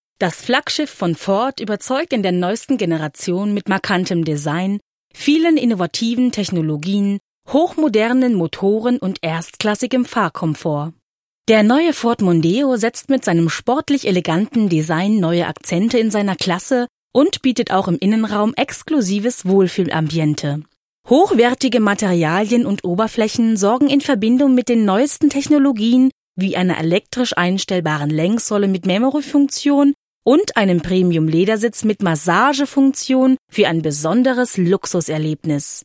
junge Stimme, variabel, dynamisch, sinnlich
Kein Dialekt
Sprechprobe: Industrie (Muttersprache):